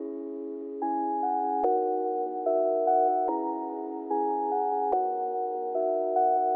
Goes nice with pianos, plucks, pads and guitars.
Tag: 146 bpm Trap Loops Piano Loops 1.11 MB wav Key : C FL Studio